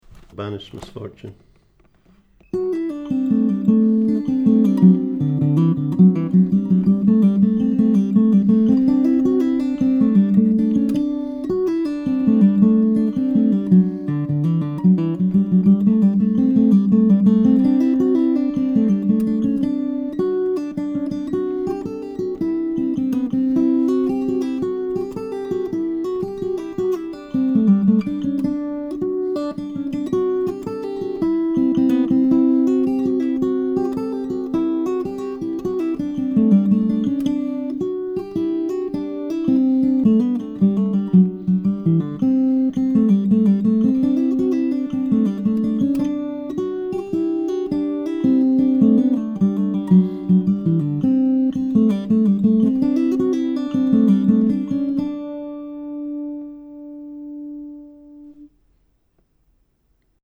DIGITAL SHEET MUSIC - FINGERPICKING GUITAR SOLO
Celtic session tune, DADGAD tuning